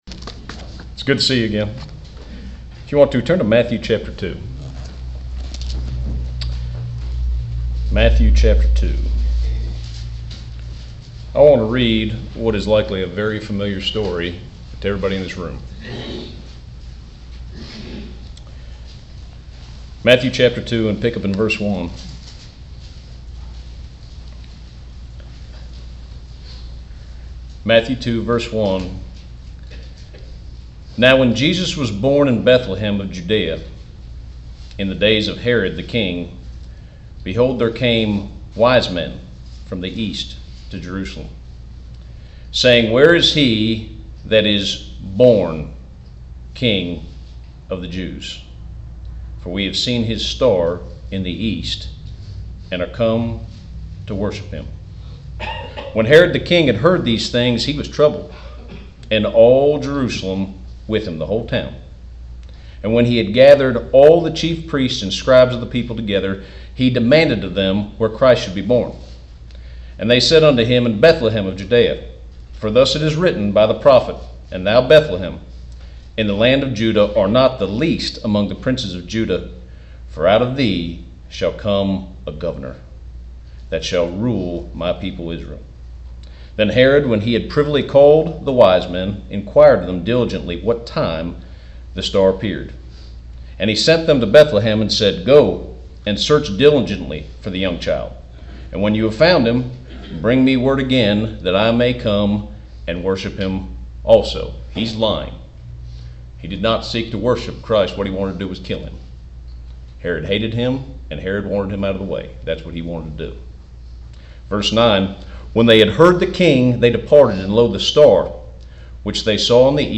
The King is born | SermonAudio Broadcaster is Live View the Live Stream Share this sermon Disabled by adblocker Copy URL Copied!